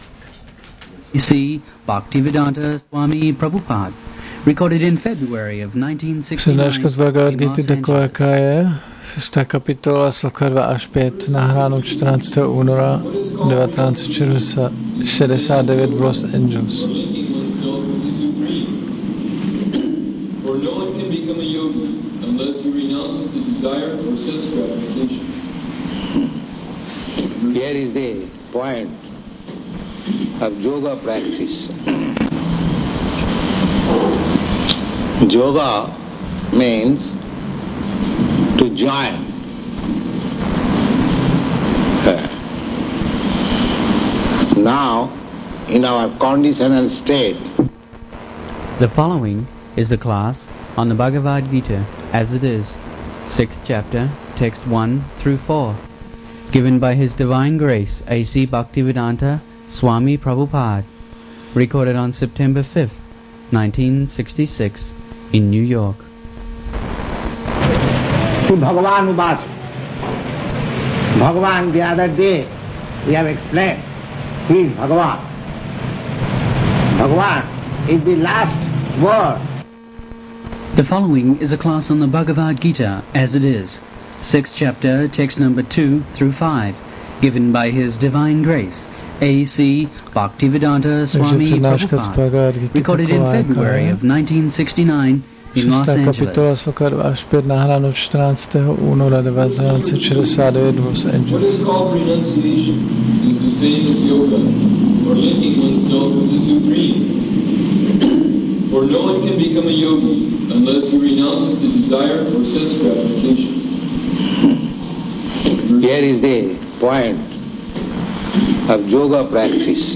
1969-02-14-ACPP Šríla Prabhupáda – Přednáška BG-6.2-5 Los Angeles